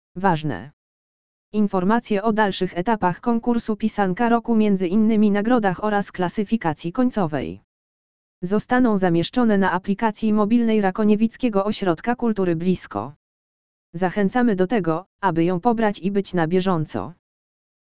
lektor_czytajacy_tekst_artykulu.mp3